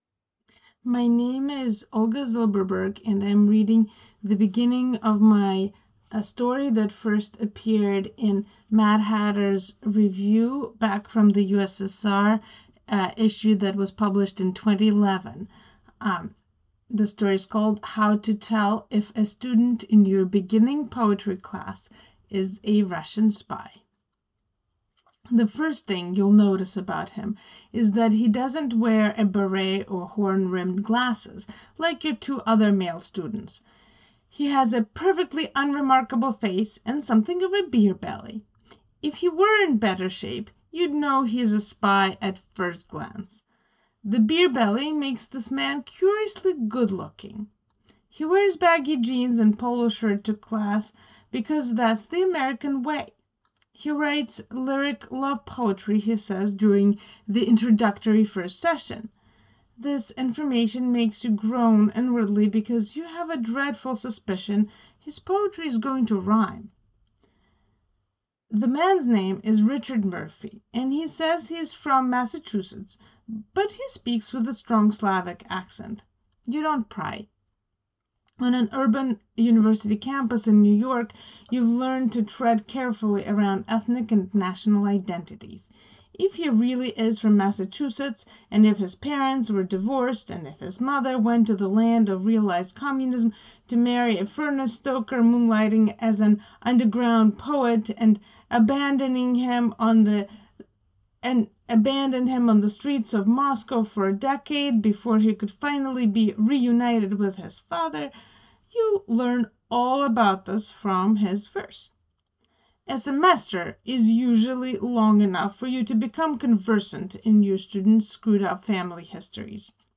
Or you can let them read to you!